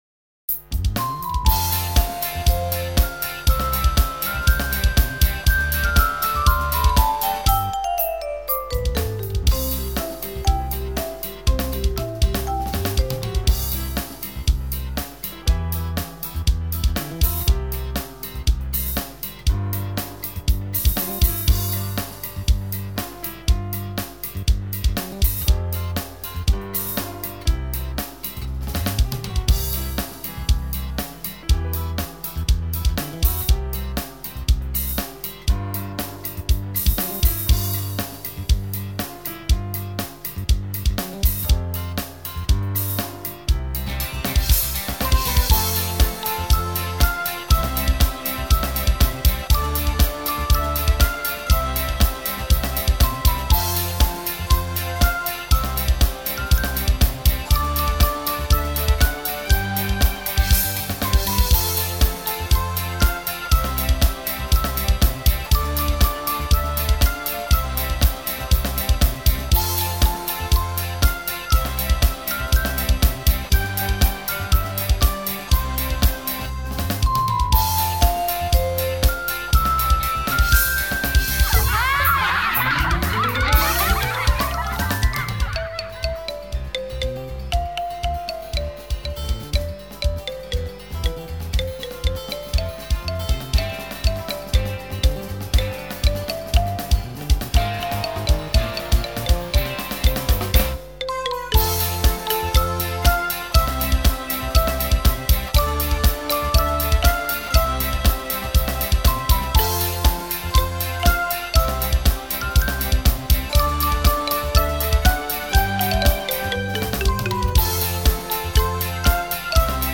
Himna-Ogledalce-Instrumental-1-1.mp3